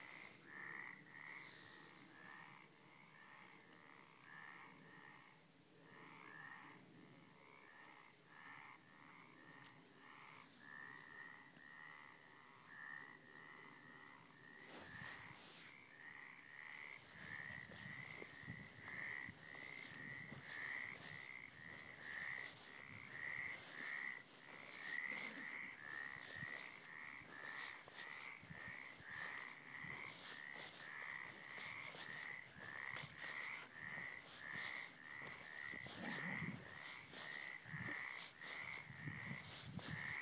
Frogs in our pond